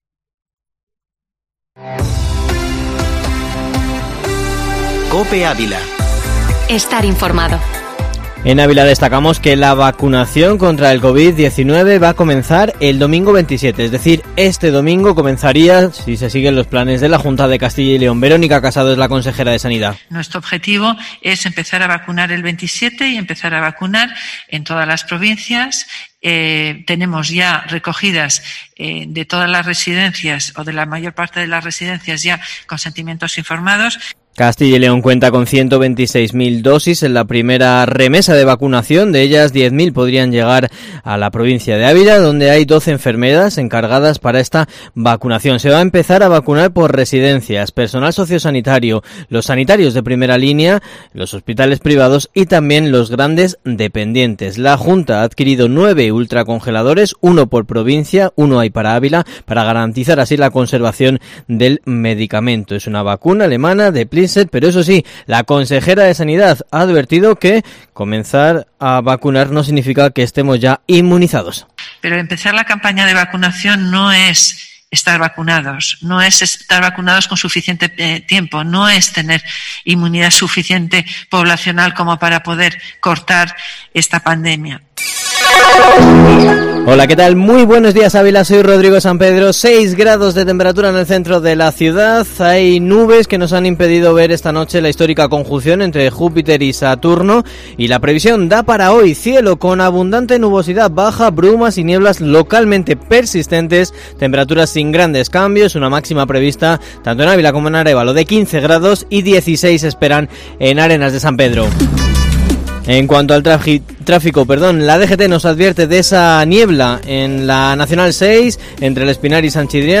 Informativo Matinal Herrera en COPE Ávila 22/12/2020